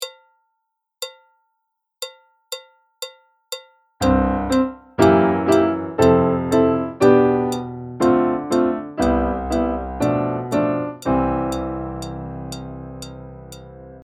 ↓課題曲のサンプル音源はこちら↓
きらきら星ジャズアレンジ